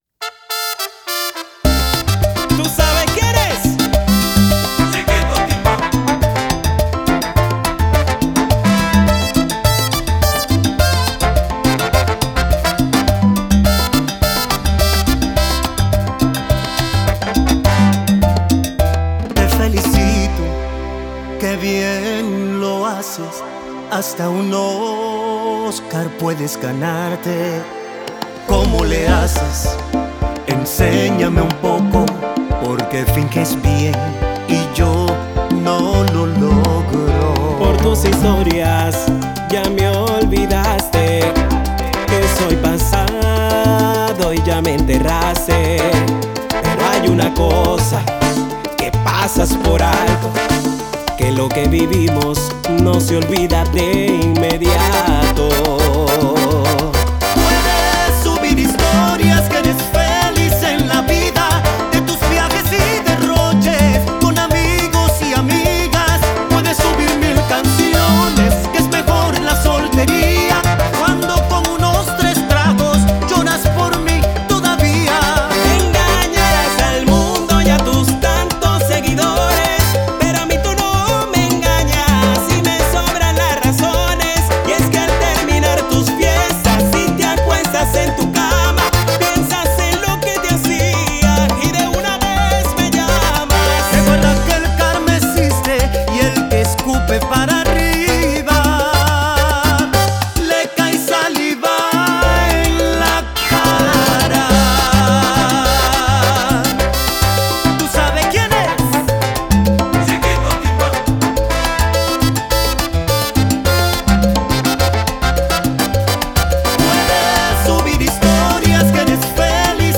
agrupación salsera
mezclado y masterizado en Colombia
versión en Dolby Atmos
salsa contemporánea